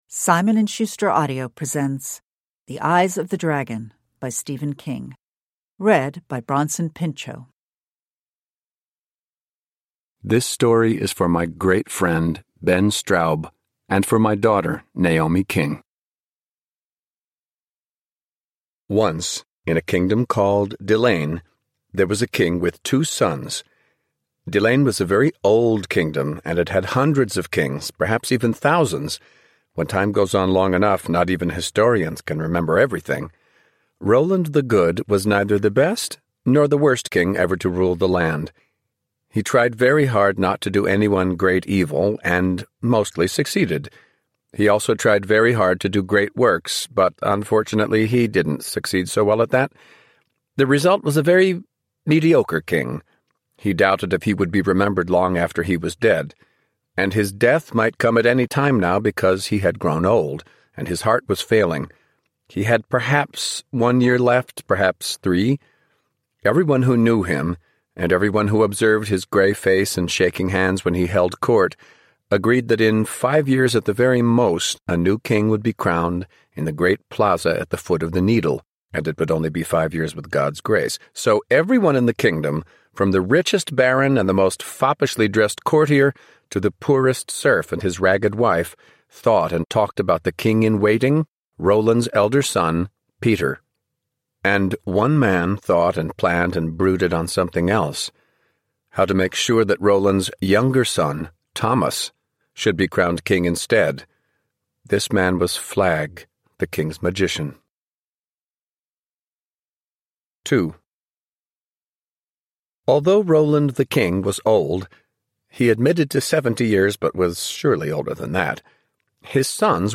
The Eyes of the Dragon (ljudbok) av Stephen King